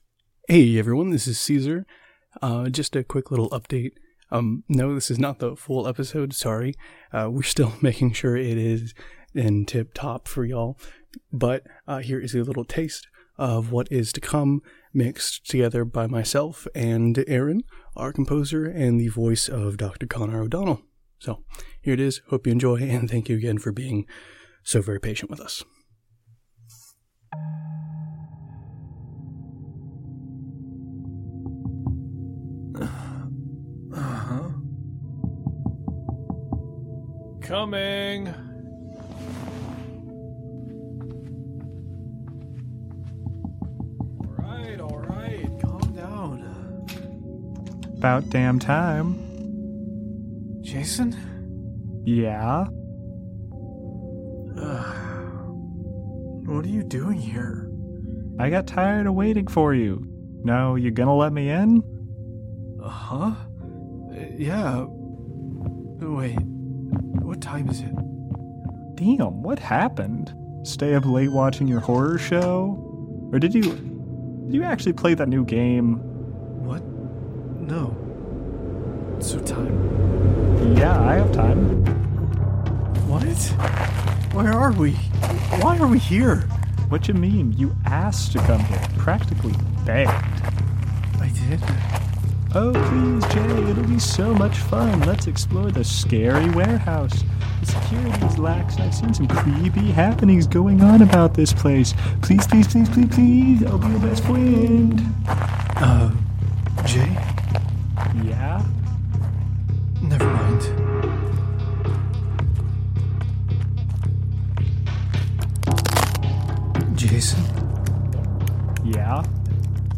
Play Rate Apps Listened List Bookmark Share Get this podcast via API From The Podcast Whispers From the Void Whispers from the Void is an immersive audio drama that plunges listeners into a world where the boundaries between reality and the supernatural are blurred.